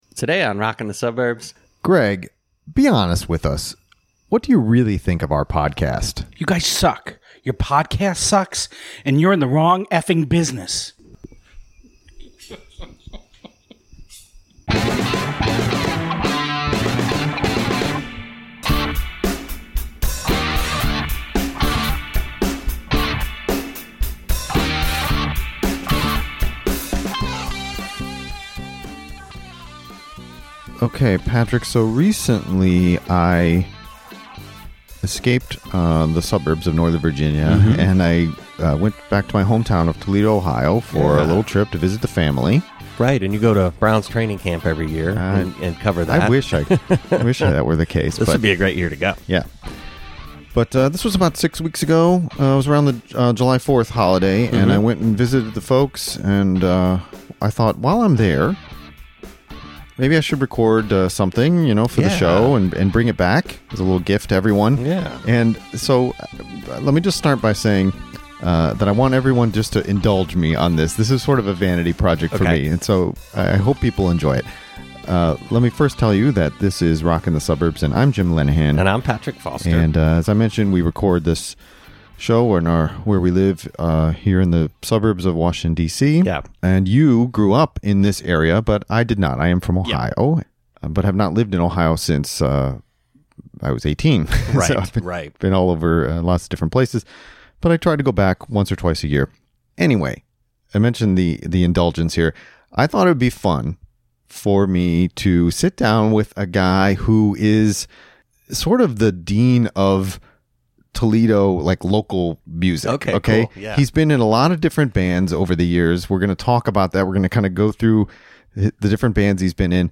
Rockin' the Suburbs / Interview